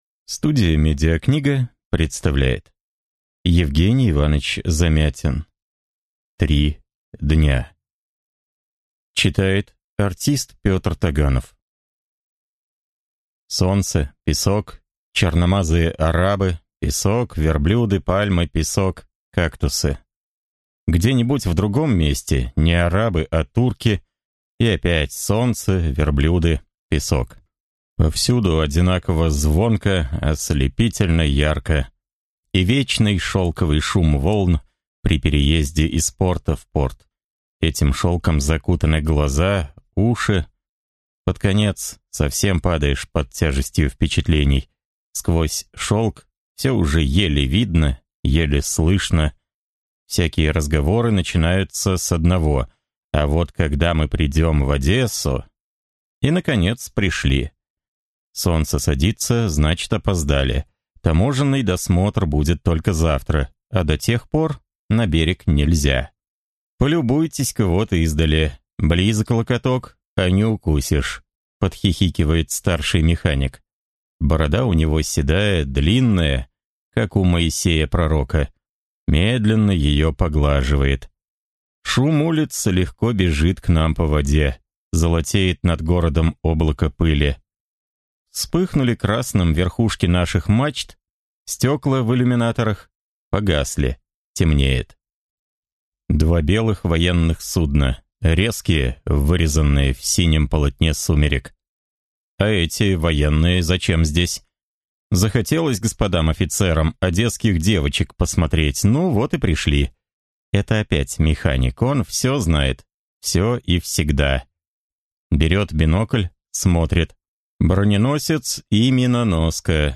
Аудиокнига Три дня. Девушка. Чрево | Библиотека аудиокниг